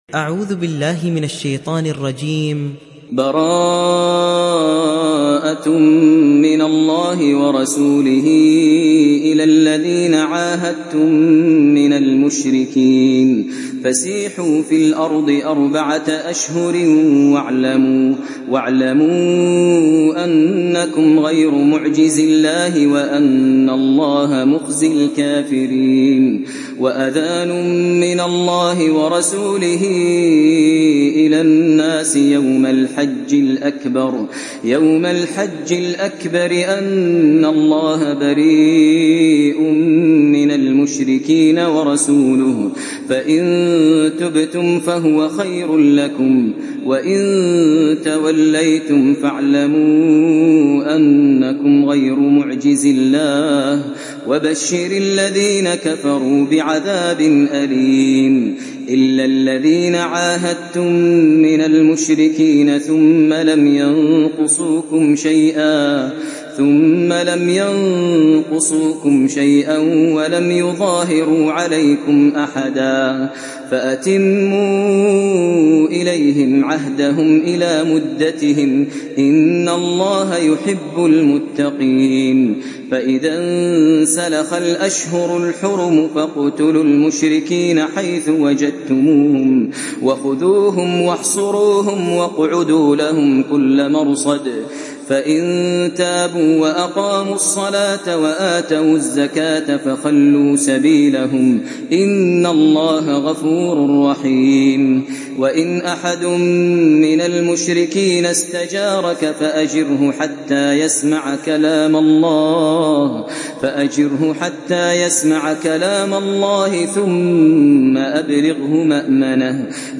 সূরা আত-তাওবাহ্ ডাউনলোড mp3 Maher Al Muaiqly উপন্যাস Hafs থেকে Asim, ডাউনলোড করুন এবং কুরআন শুনুন mp3 সম্পূর্ণ সরাসরি লিঙ্ক